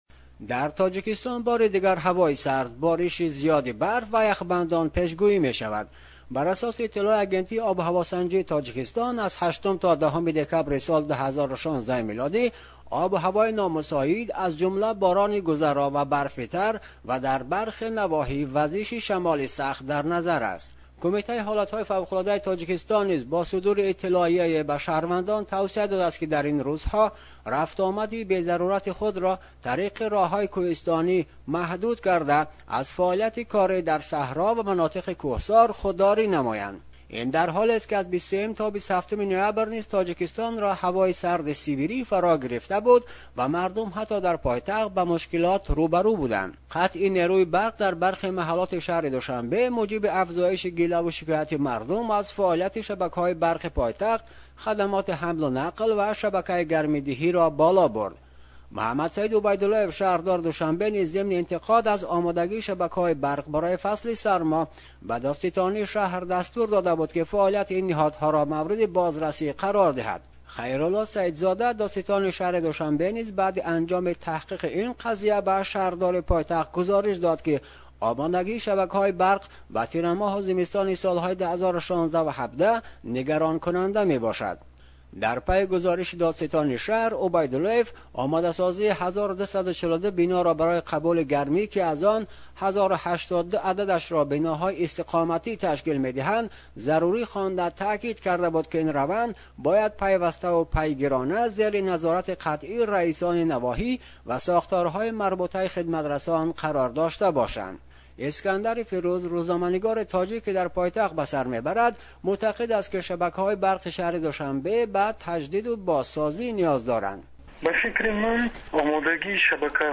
аз Душанбе дар ин бора гузориш медиҳад